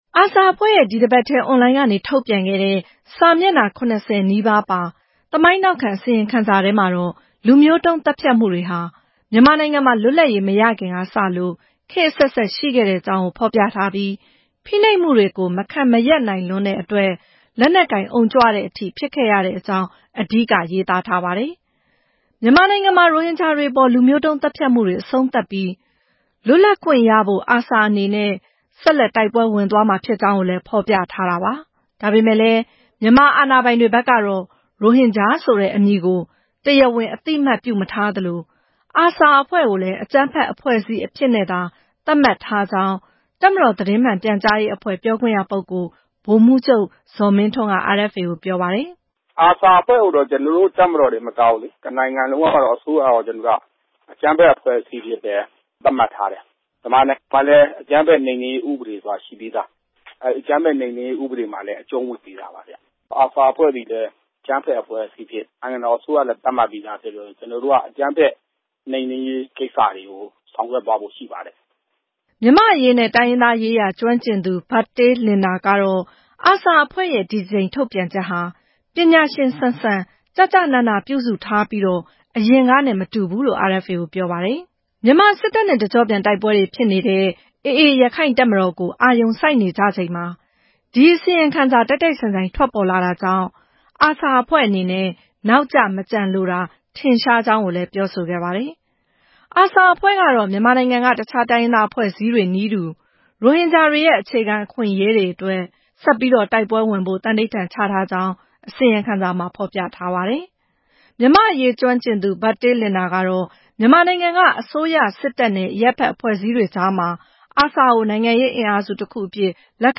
အသေးစိတ်ကို မြန်မာ့အရေးကျွမ်းကျင်သူတွေနဲ့ မေးမြန်းပြီး